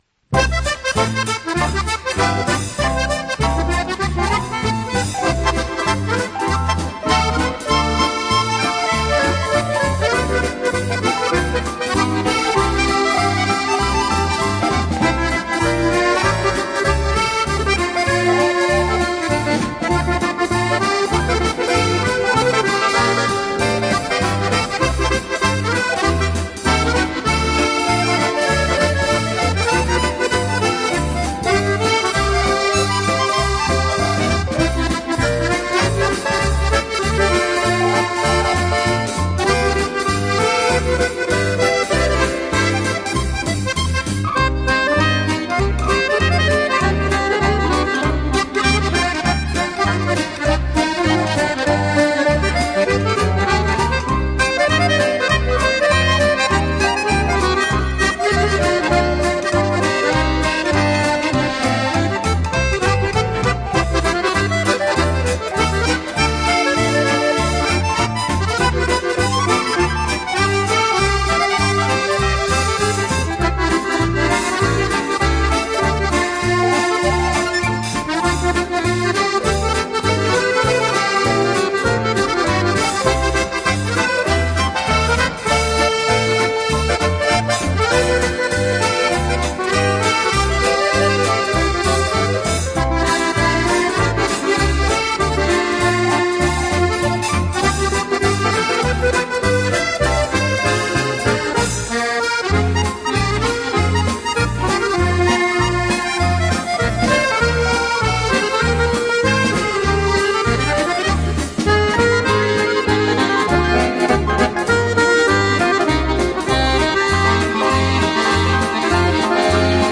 Жанр: Easy Listening, Accordeon